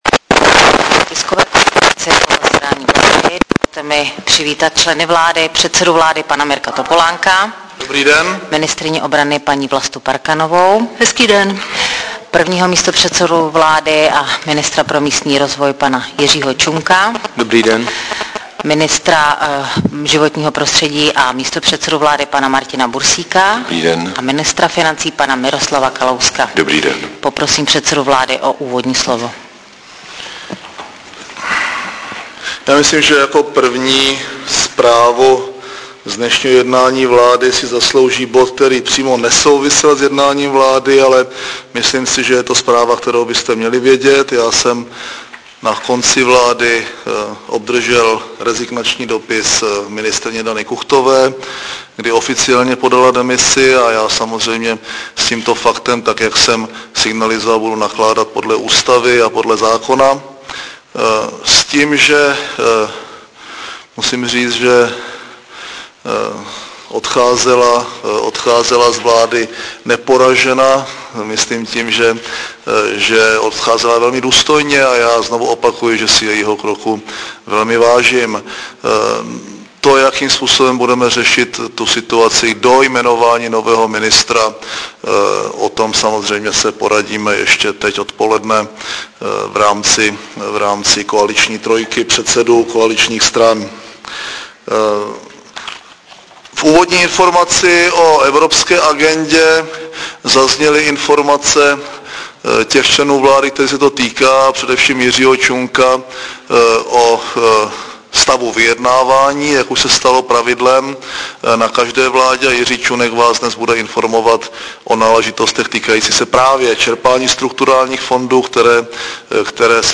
Tisková konference předsedy vlády ČR Mirka Topolánka po jednání vlády ve středu 3. října 2007